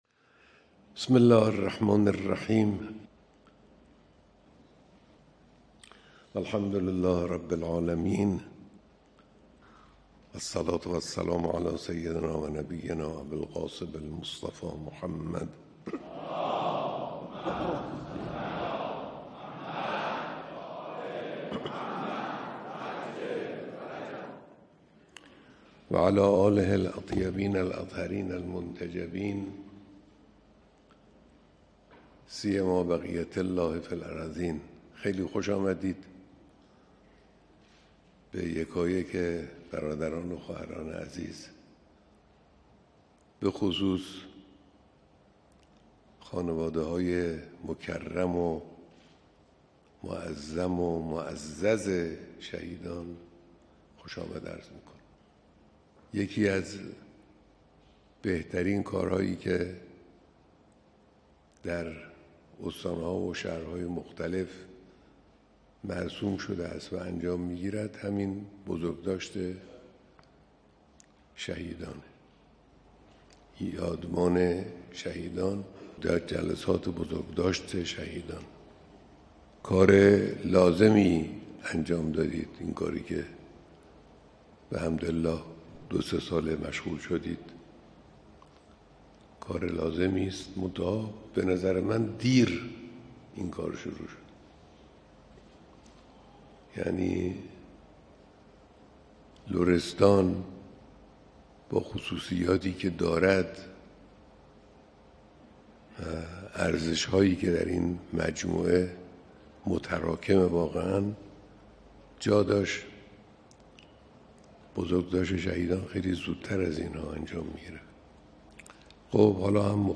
بیانات در دیدار اعضای کنگره بزرگداشت شهدای استان لرستان